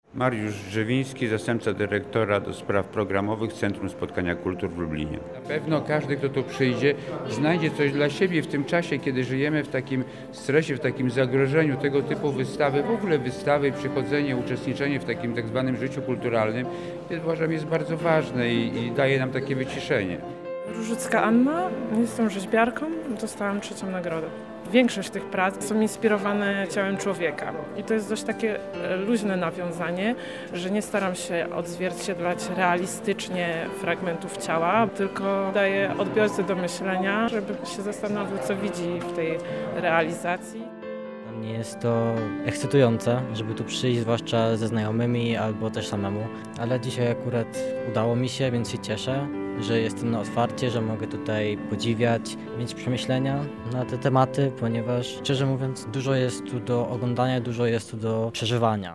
Na miejscu był nasz reporter.